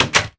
door_close.ogg